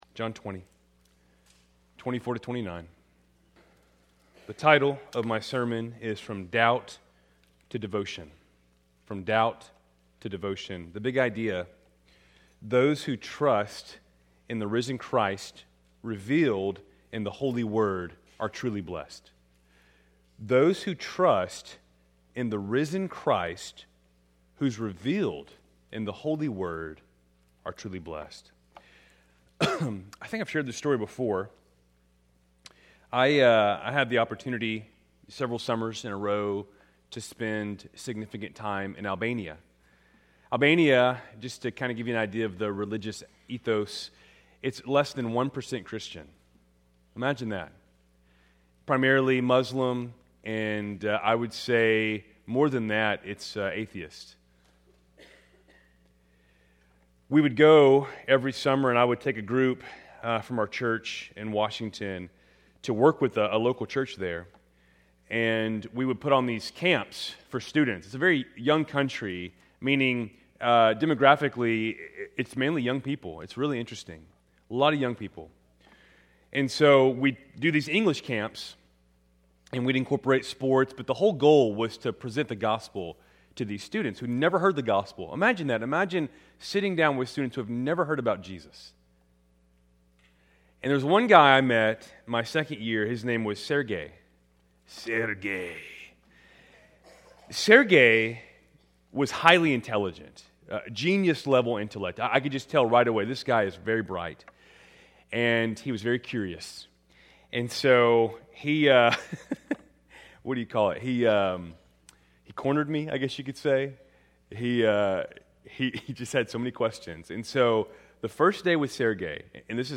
Keltys Worship Service, March 22, 2026